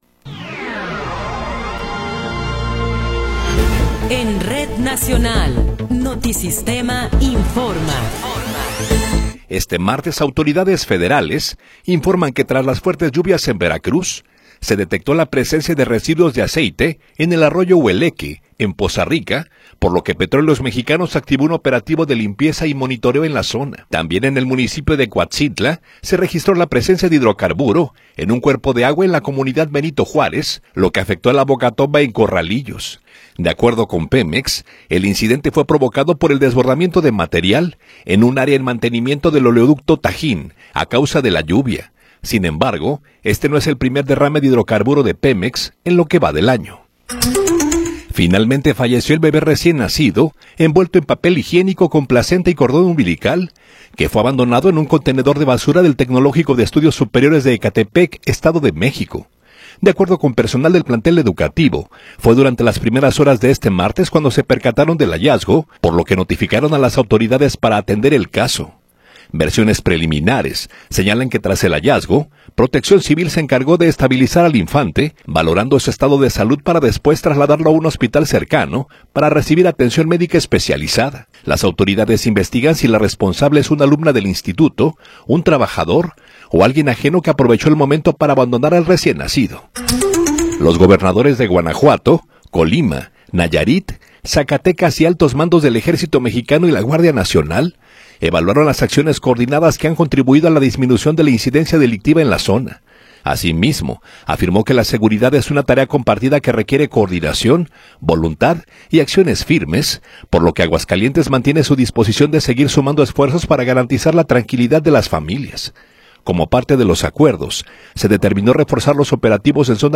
Noticiero 18 hrs. – 14 de Abril de 2026